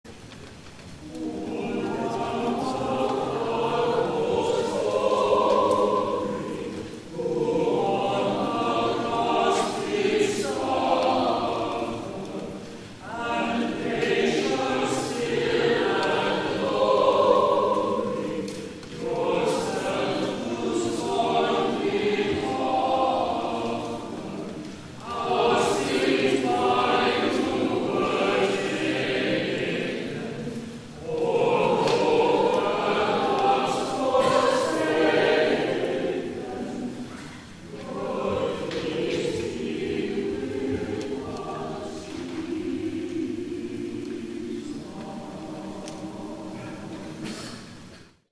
*THE CHORAL RESPONSE